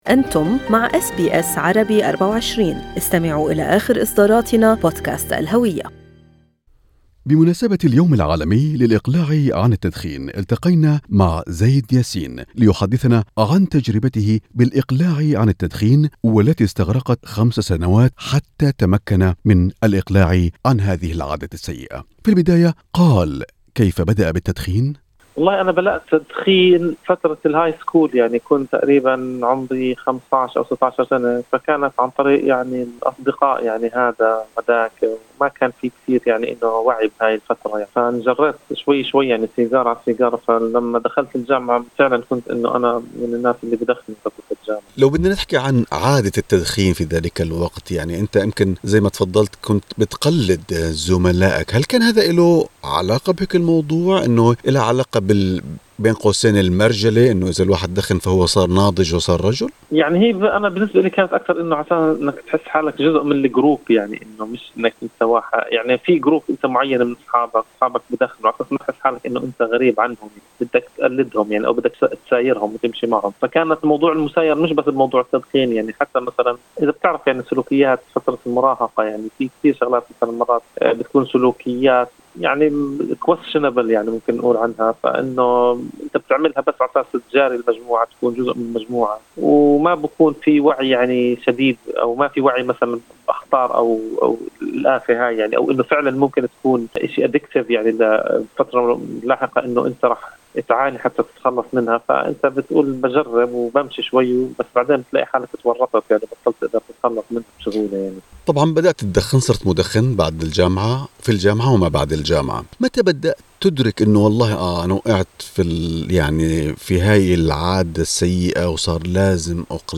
أس بي أس عربي View Podcast Series